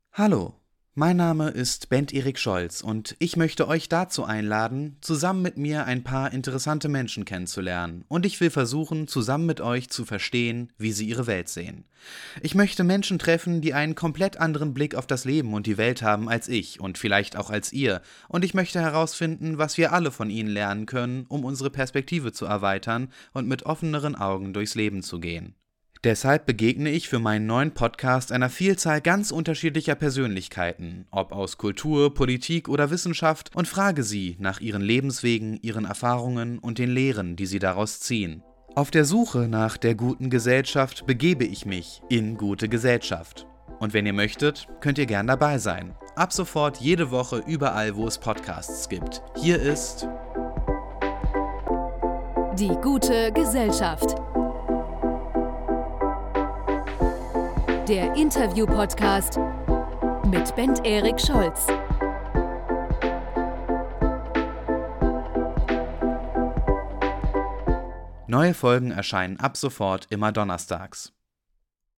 Der Interview-Podcast